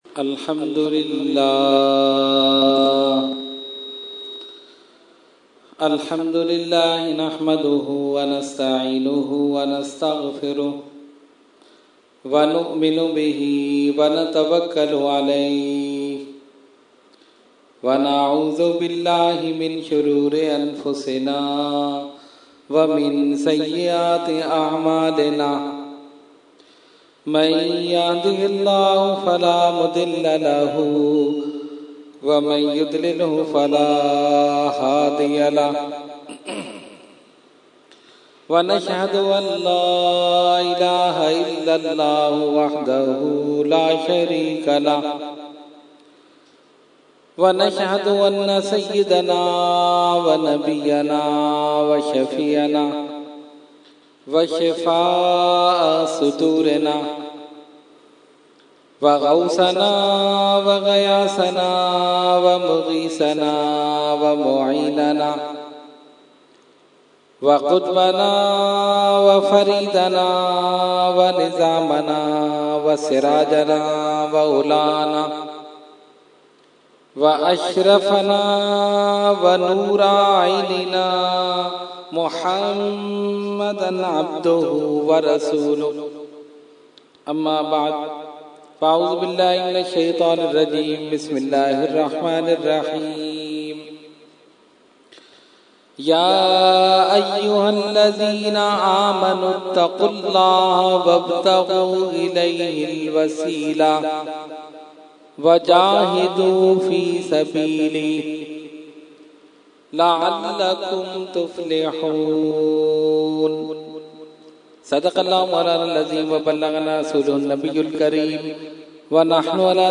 Category : Speech